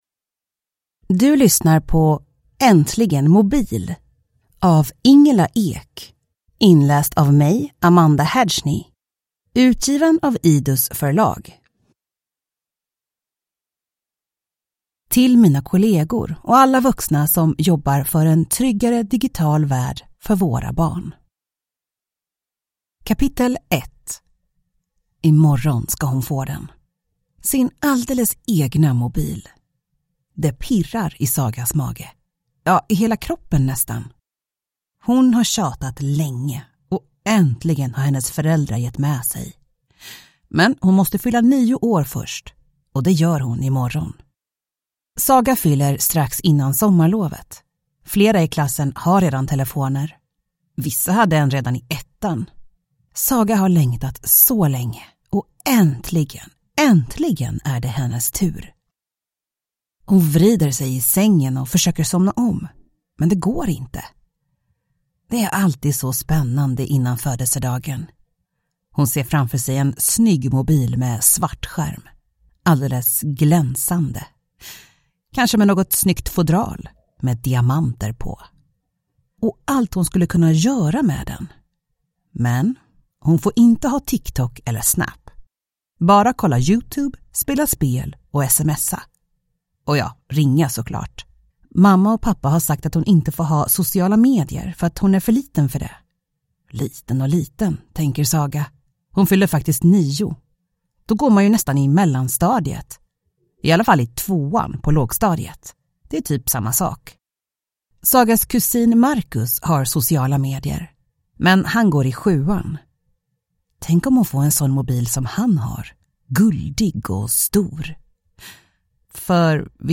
Äntligen mobil! – Ljudbok